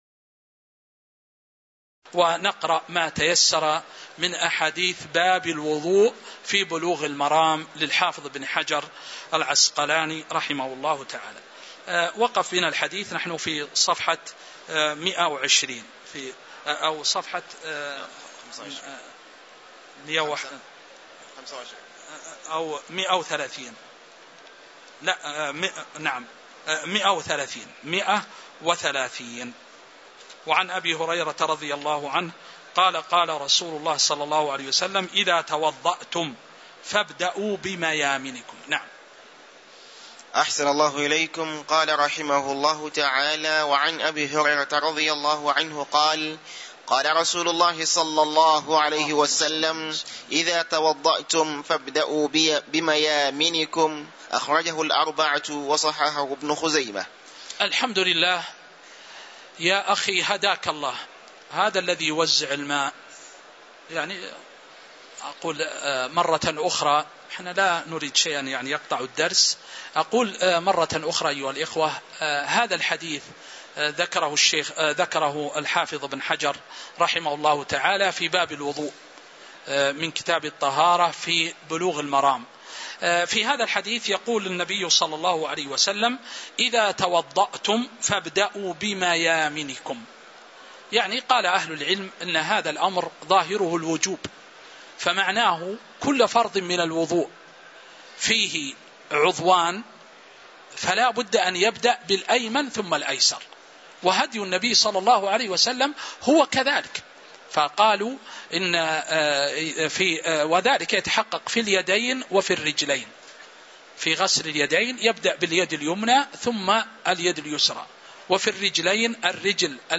تاريخ النشر ١٤ ذو القعدة ١٤٤٤ هـ المكان: المسجد النبوي الشيخ